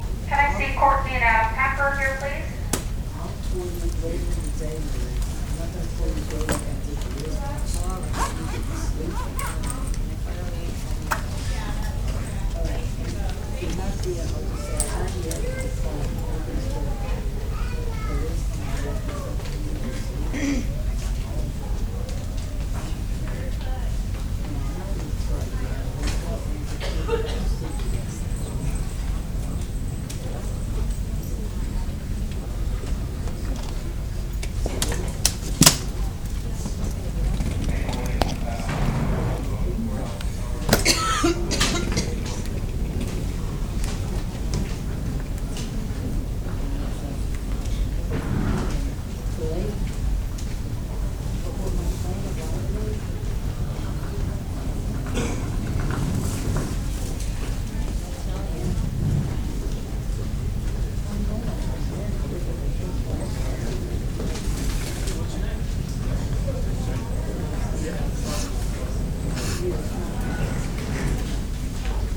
airport – Hofstra Drama 20 – Sound for the Theatre
Sounds Heard : Backround rumble, chatter from airport passengers, children crying, suitcases clicking on floor
field-recording1.mp3